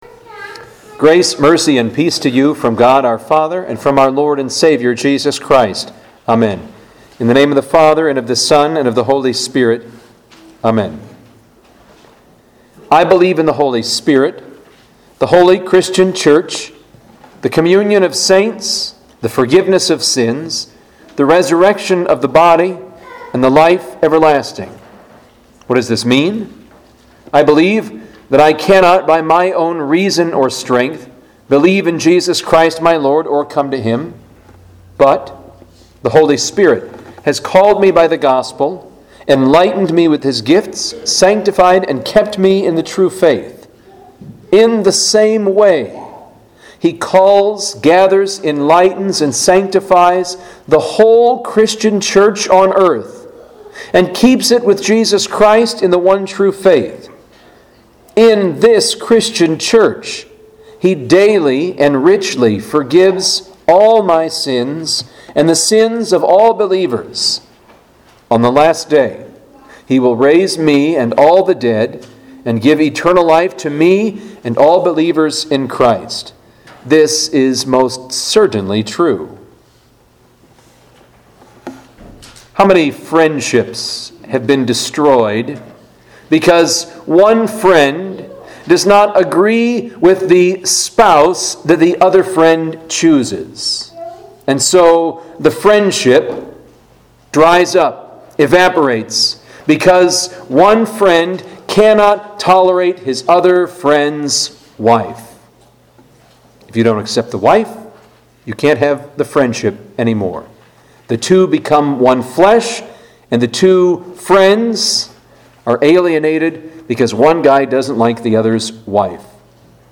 Home › Sermons › Judica Wednesday